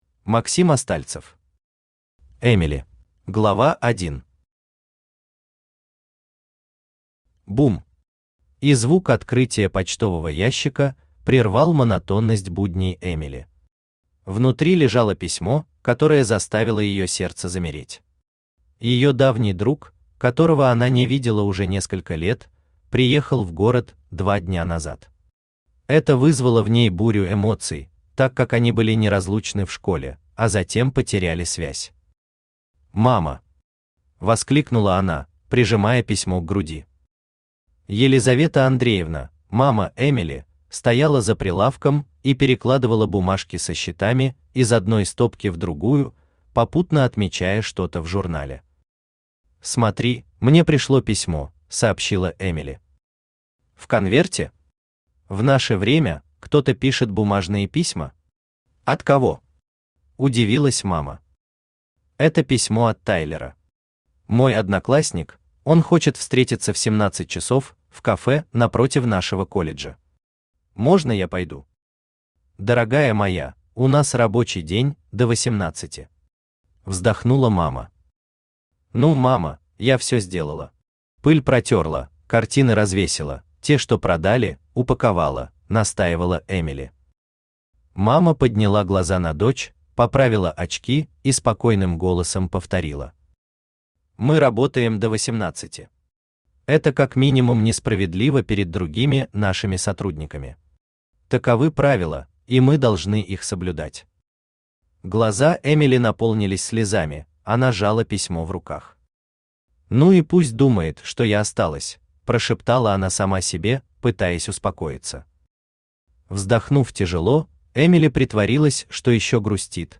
Аудиокнига Эмели | Библиотека аудиокниг
Aудиокнига Эмели Автор Максим Остальцев Читает аудиокнигу Авточтец ЛитРес.